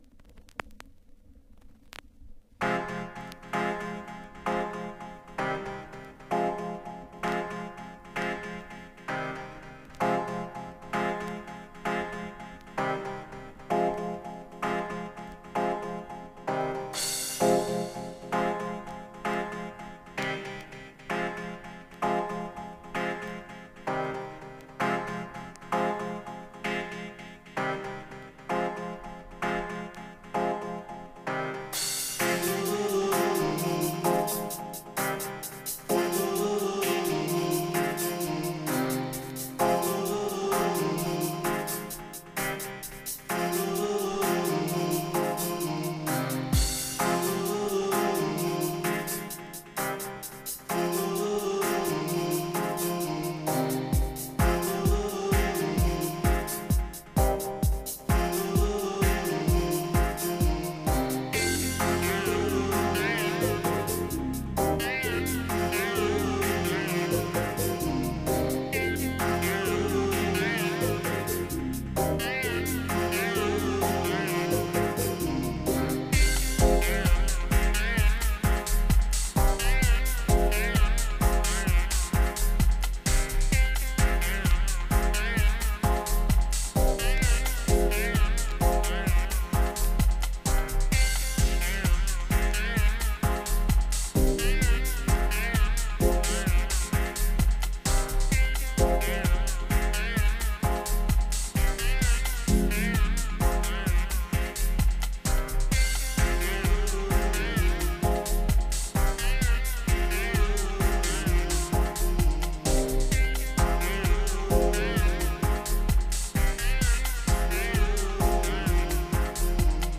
has tracks from uk dub legends